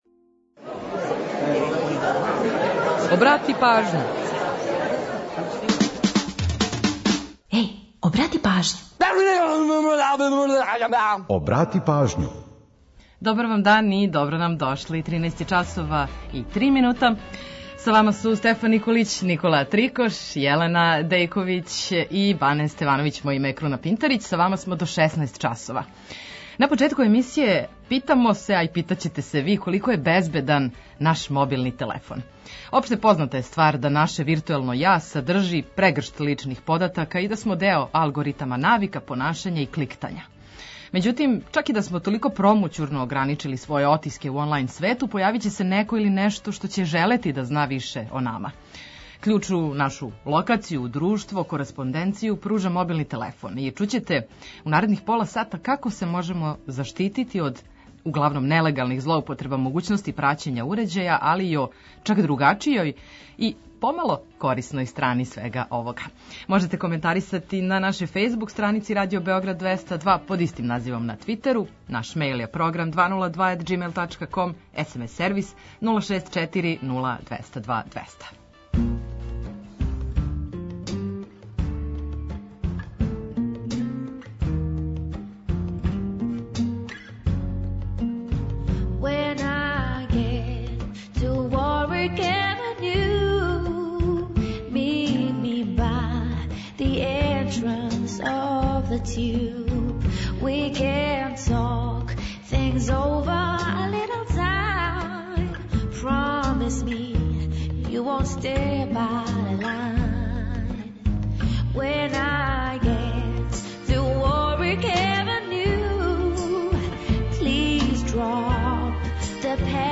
Ту је и пола сата песама само из Србије и региона, новитети са топ листа, приче о песмама и наш репортер са актуелним градским информацијама.